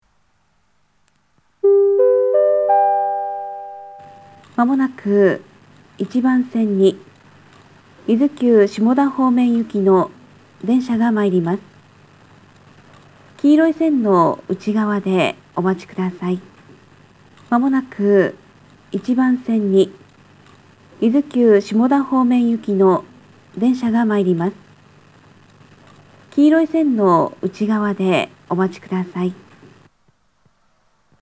自動放送タイプ
（女性）
下り接近放送